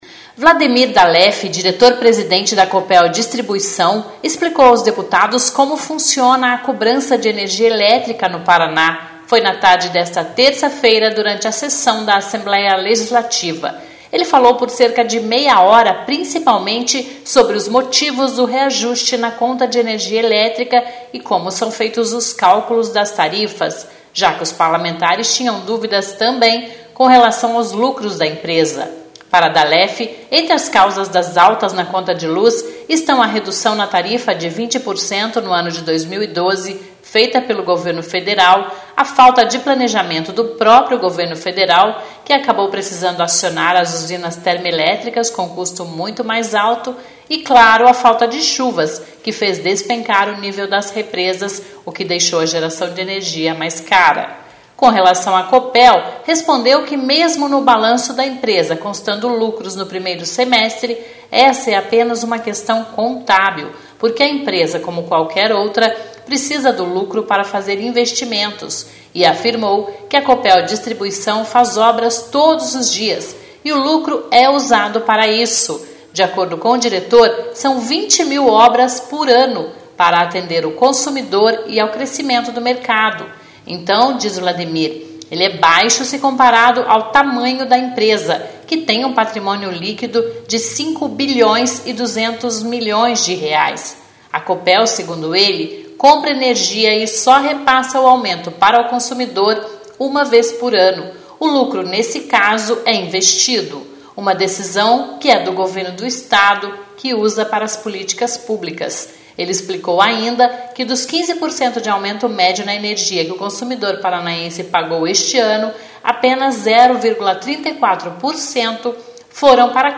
Foi na tarde desta terça-feira (18) durante a sessão  da Assembleia Legislativa.
(sonora)